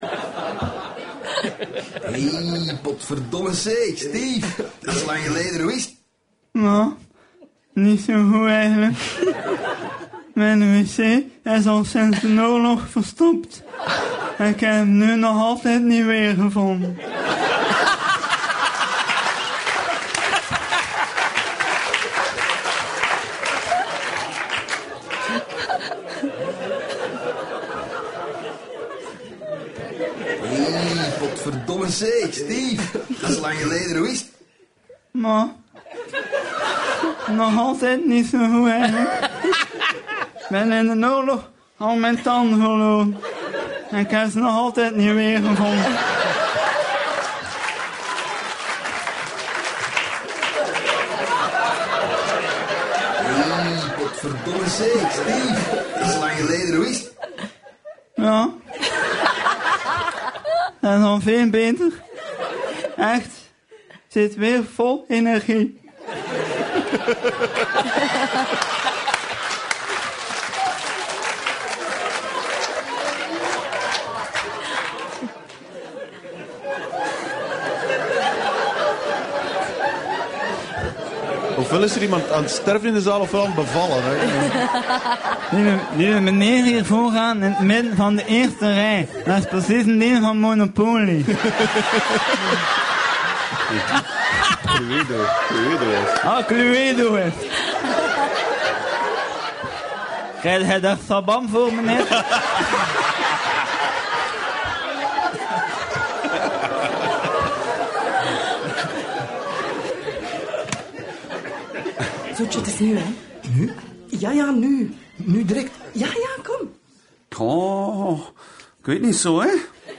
Je hoeft er uiteraard je hoofd niet over te breken en kan onmiddellijk meegenieten van enkele fragmenten uit “De Raadkamer”.